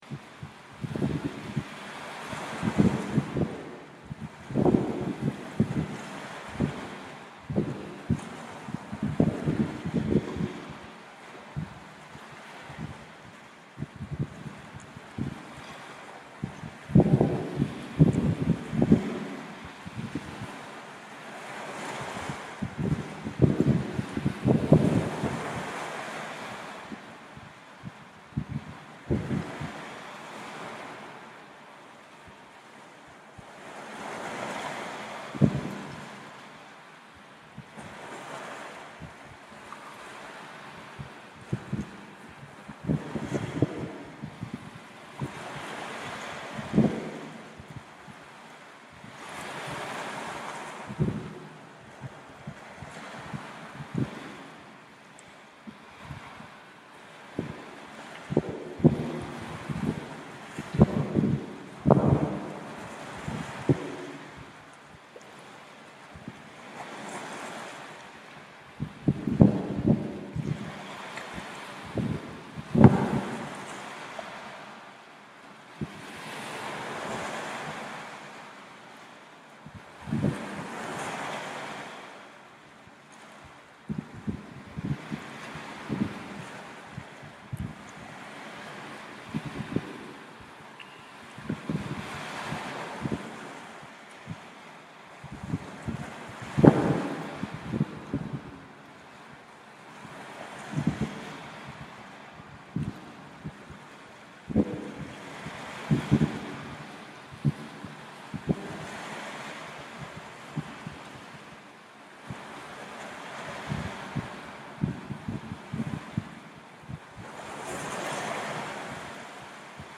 Field recording of waves from the mostly-uninhabited Greek island of Delos